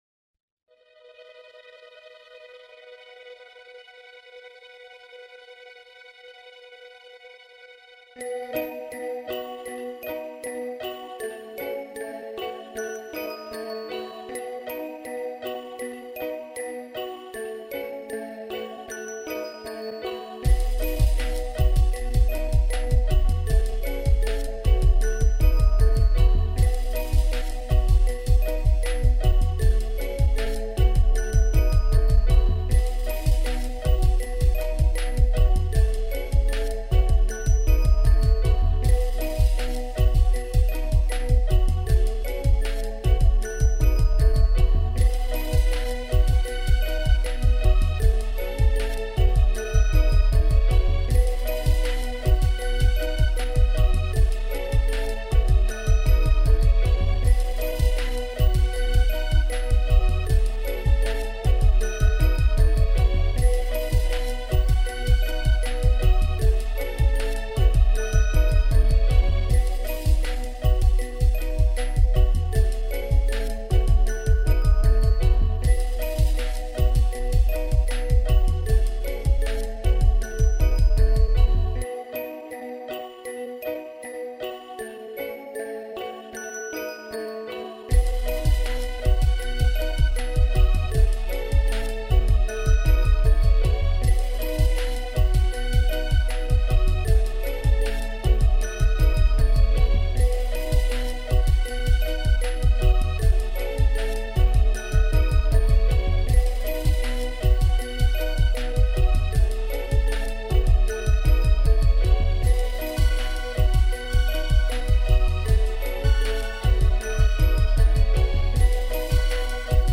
dance/electronic
Ambient
Dancehall
Dub/Dubstep